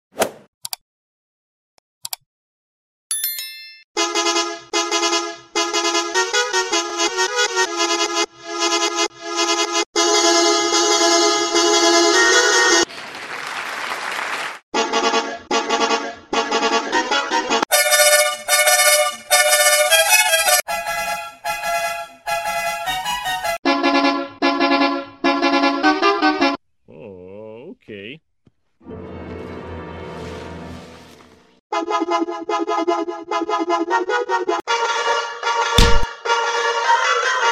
9 "Belaz Horn” Sound Variations